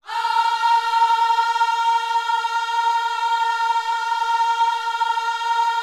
OHS A#4C  -R.wav